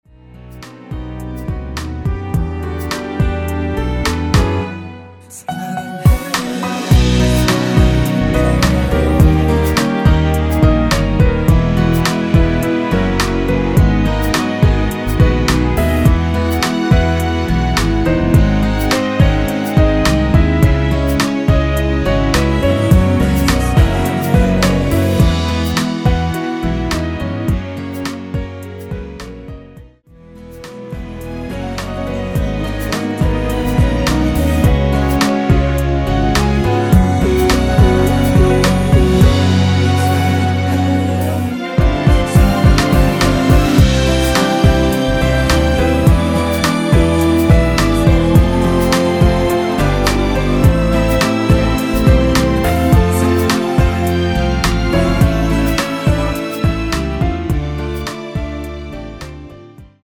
원키에서(-1)내린 코러스 포함된 MR 입니다.(미리듣기 확인)
◈ 곡명 옆 (-1)은 반음 내림, (+1)은 반음 올림 입니다.
앞부분30초, 뒷부분30초씩 편집해서 올려 드리고 있습니다.
중간에 음이 끈어지고 다시 나오는 이유는